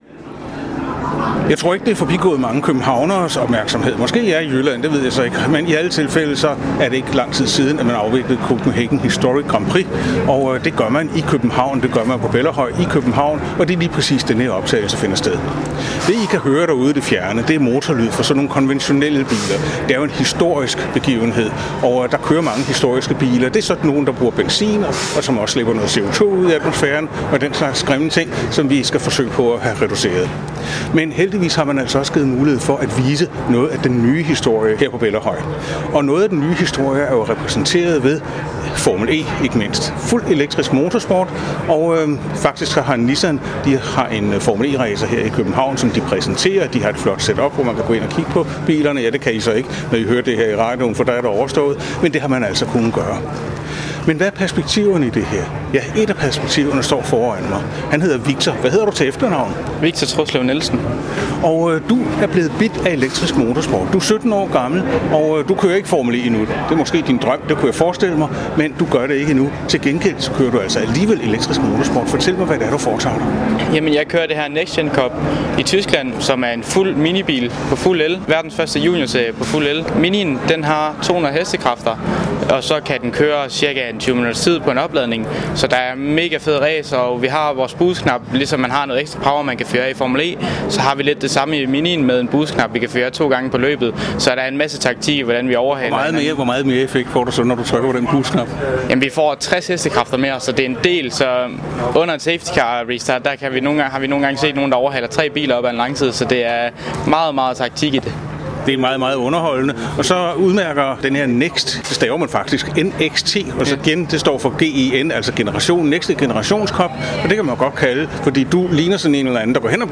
Intw